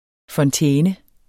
Udtale [ fʌnˈtεːnə ]